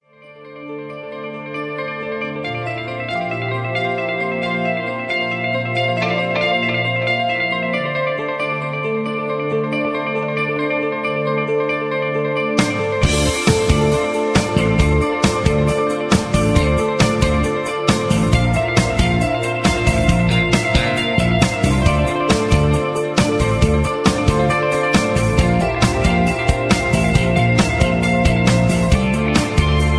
rock and roll, rock